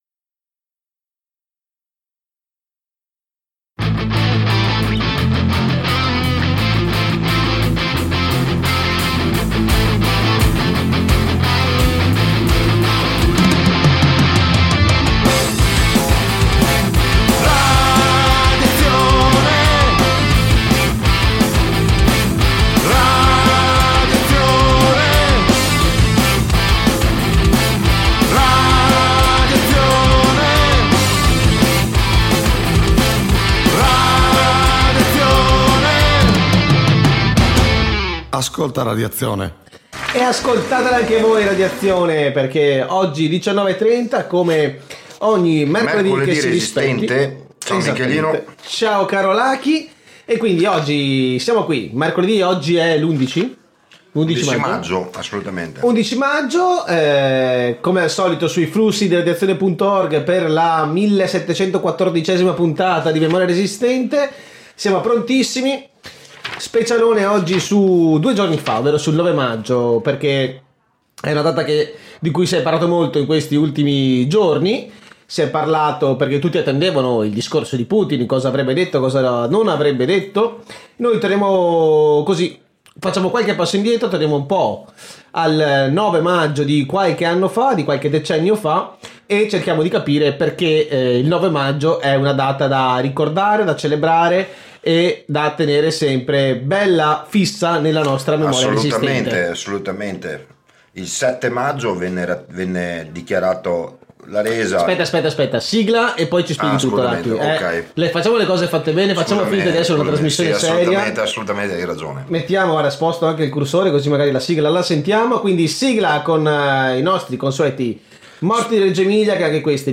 Puntatona scoppiettante di Memoria Resistente dedicata al 9 maggio 1945, una data da non dimenticare che raccontiamo attraverso le parole e gli scarponi dei soldati sovietici che hanno combattuto e sconfitto il nazifascismo. Leggiamo alcuni racconti dal libro “Il prezzo della vittoria” per un 9 maggio che non si deve dimenticare.